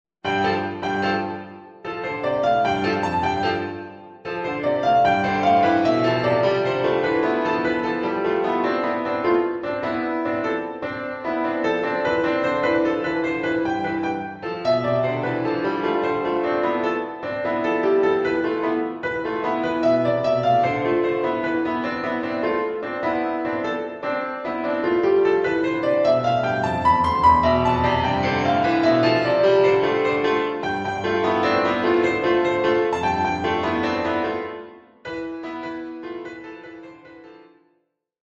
Three clever animal inspired pieces for piano.